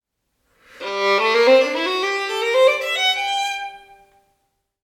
Mit den sogenannten Durtonleitern assoziieren wir eine fröhliche, mit Molltonleitern eine traurige Stimmung:
Molltonleiter